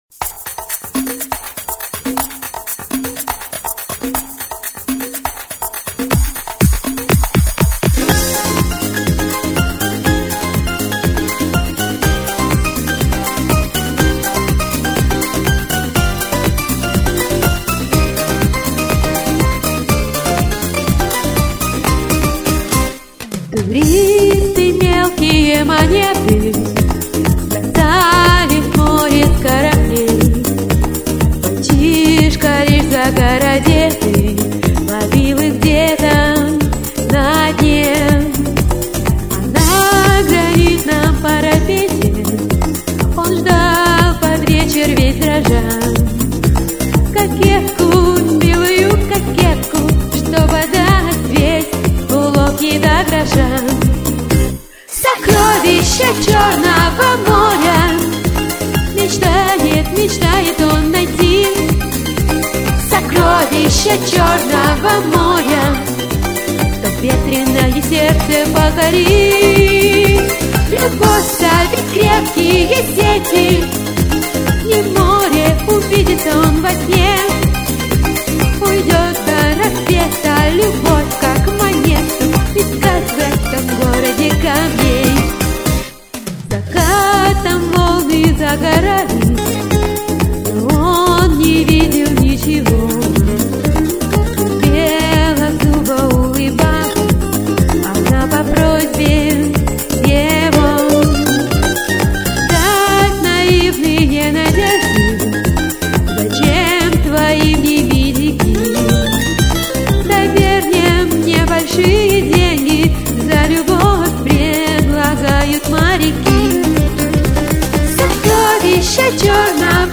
Понравились обе исполнительницы!